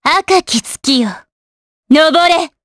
Xerah-Vox_Skill7_jp.wav